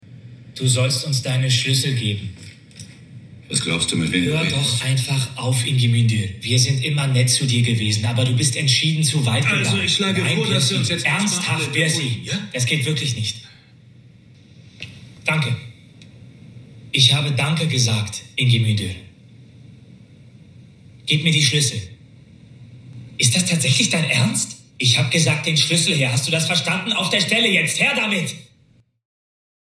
2022 - Weißer Weißer Tag - befehlerisch / selbstbewusst